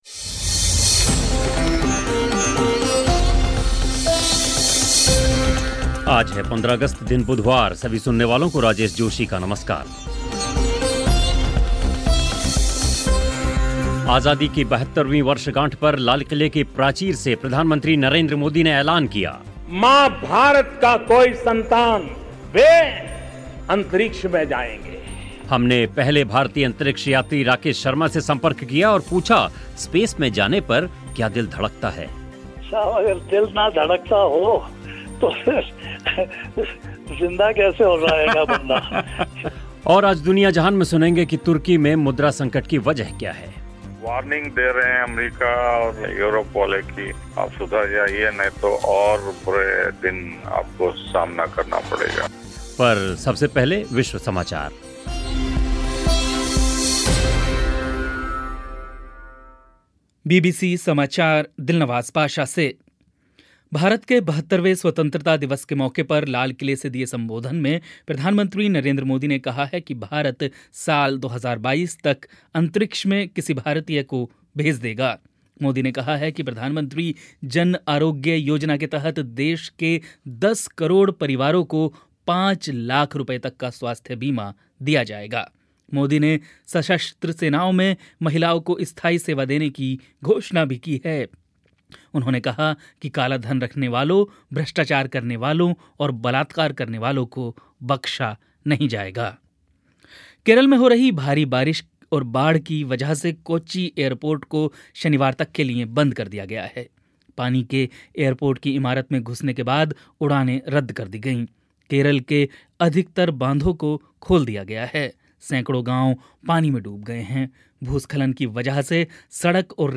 हमने पहले भारतीय अंतरिक्ष यात्री राकेश शर्मा से संपर्क किया और पूछा स्पेस में जाने पर दिल धड़कता है? पूरा इंटरव्यू होगा कार्यक्रम में.